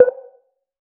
a-short-clean-ui-select-qqfb62vn.wav